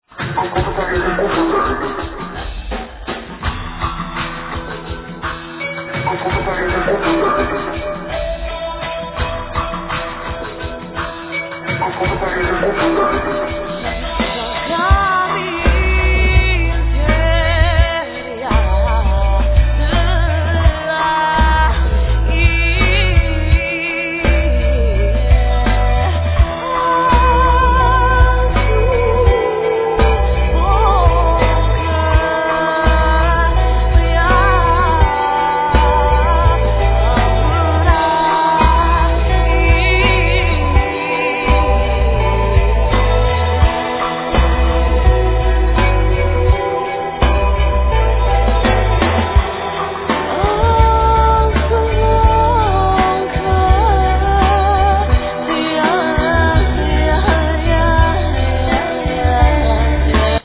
Guitar, Rhythm guitar, Guitar textures
Trapkit Drum set
Guitar, Cello, Loops, Samples
Bass, Piano
Doumbek, Riqq, Sitar, Tar
Vocals